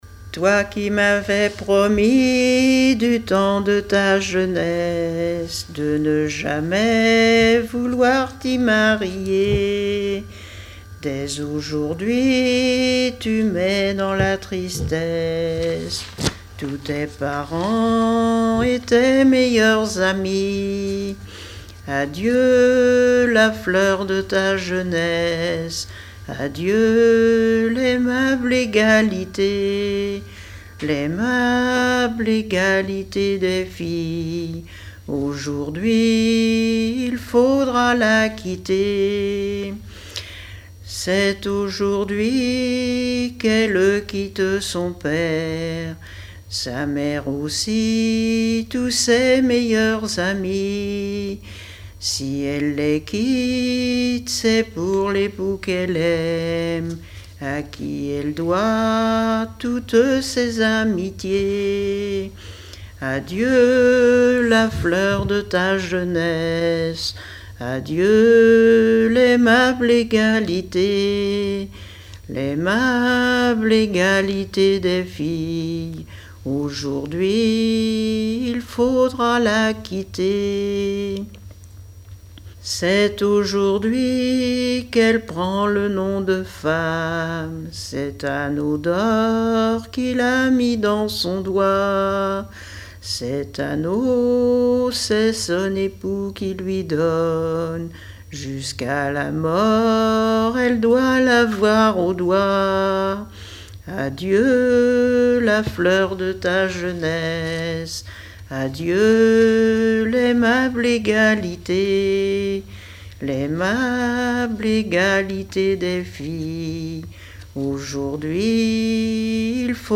Genre strophique
répertoire de chansons populaires
Pièce musicale inédite